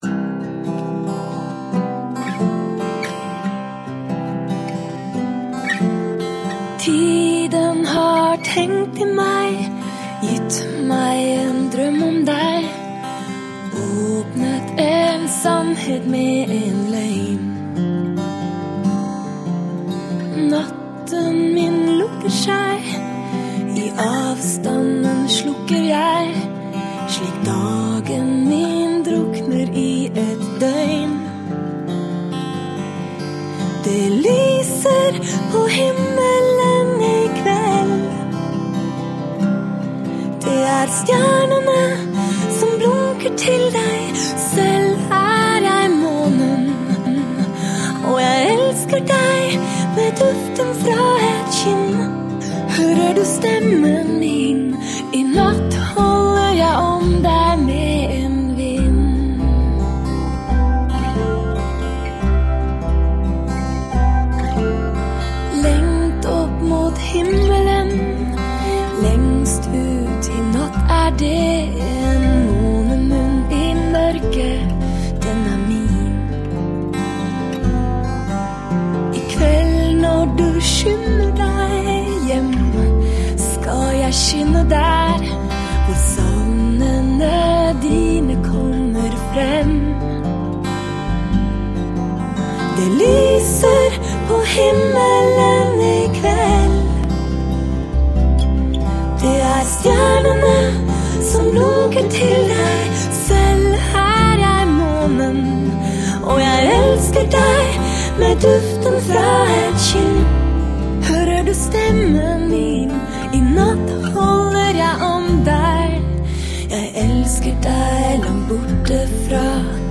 声音很独特的一个爵士女歌手
唱腔有正统爵士乐的即兴況味，但是听来却又像诗歌的轻轻吟唱，有一点嗲，但不会甜得发腻，令人感到极为放松、慵懒。